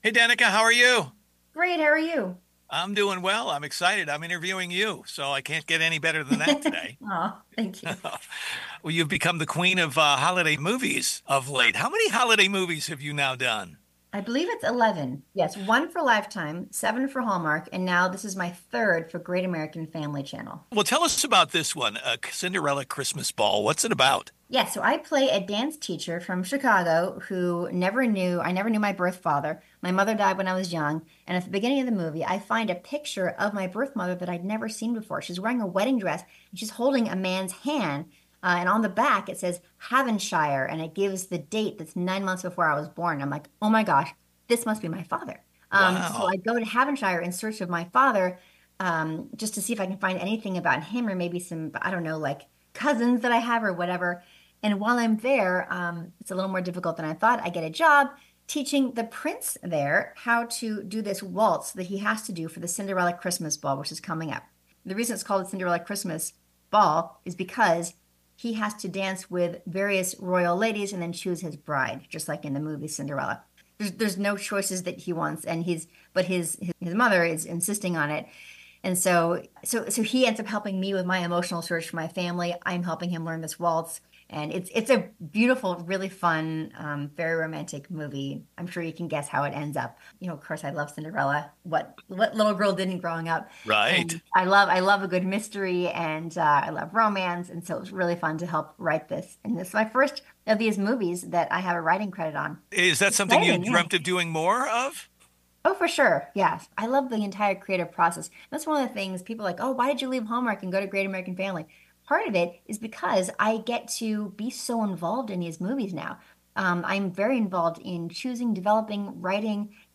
Danica McKellar – Full Interview